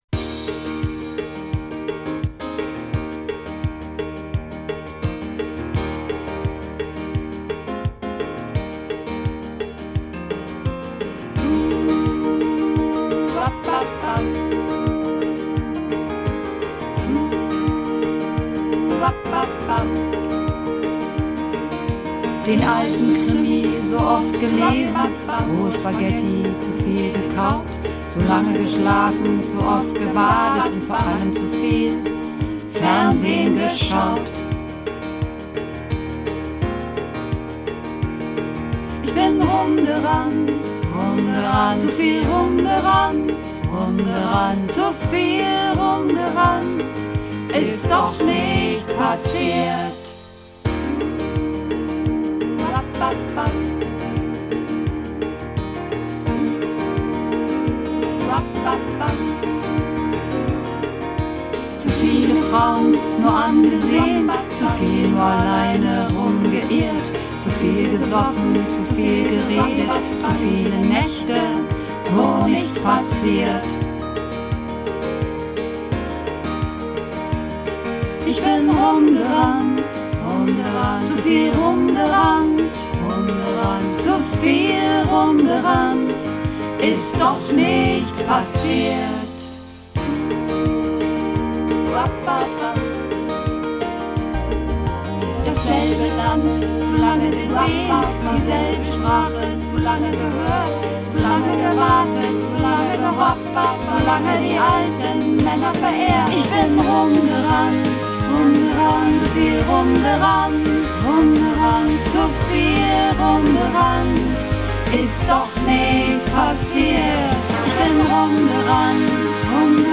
Übungsaufnahmen - Langeweile
Langeweile (Mehrstimmig)
Langeweile__5_Mehrstimmig.mp3